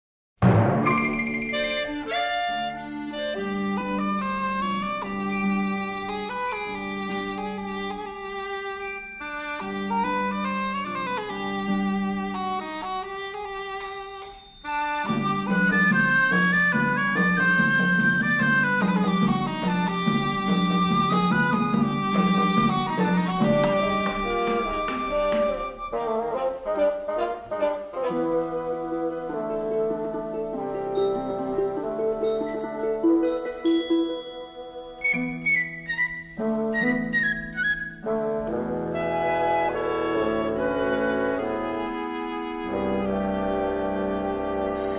released in stereo in 1959